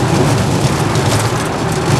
tyres_asphalt_rough.wav